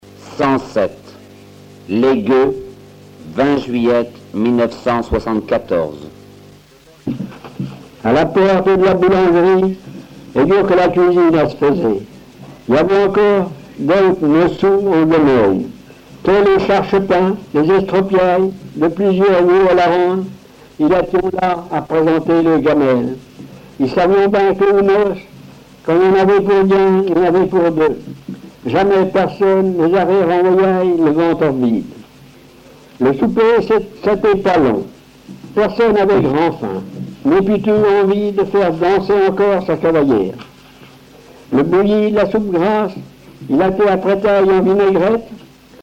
Récits en patois
Catégorie Récit